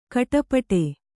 ♪ katapaṭe